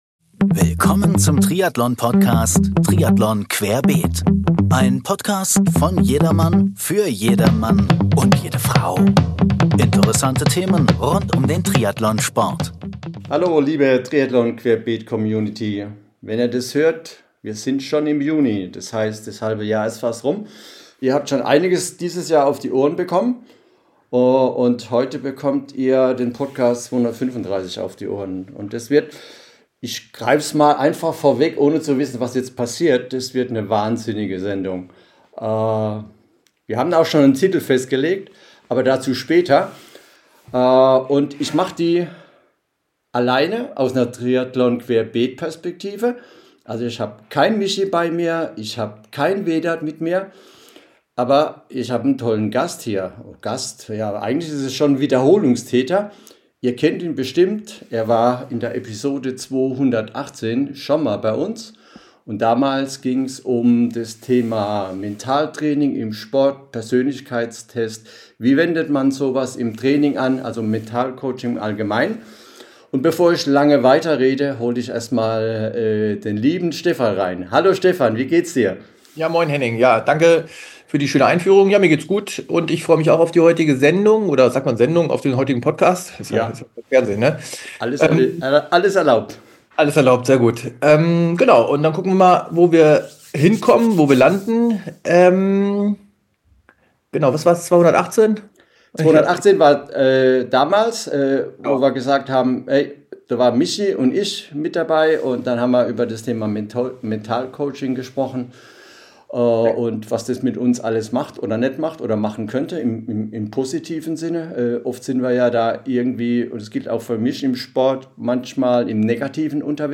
Beschreibung vor 9 Monaten In dieser Folge hört ihr ein intensives Live-Coaching, das sich ganz der mentalen Vorbereitung auf eine außergewöhnliche Herausforderung widmet: die lange und fordernde Tour zum Nordkap.